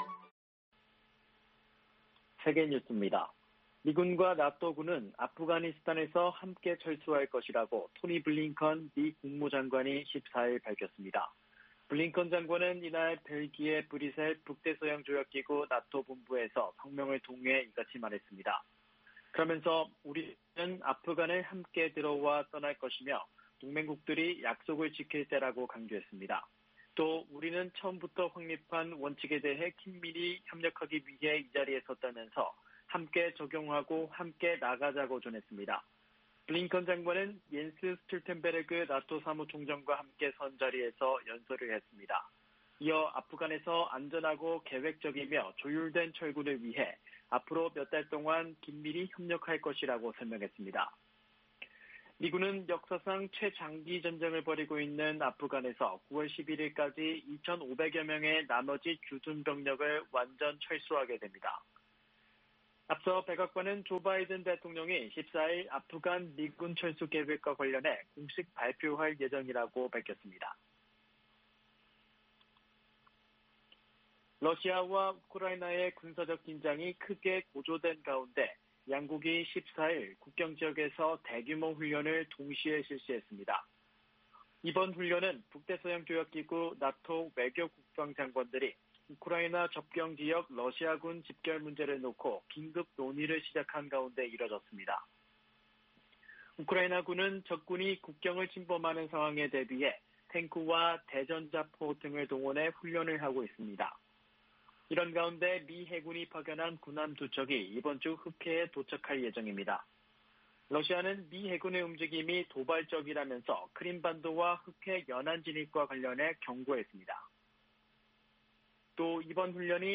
VOA 한국어 아침 뉴스 프로그램 '워싱턴 뉴스 광장' 2021년 4월 15일 방송입니다. 미국 정보당국의 연례 위협 평가 보고서는 김정은 북한 국무위원장이 미국을 압박하기 위해 올해 핵실험이나 장거리 미사일 시험발사를 재개할 수 있다고 분석했습니다. 북한은 오는 2027년까지 최대 242개의 핵무기를 보유할 것이라고, 미국과 한국의 민간 연구소가 전망했습니다. 바이든 행정부가 한국계 관리를 국무부 국제안보∙비확산 담당 차관보로 지명했습니다.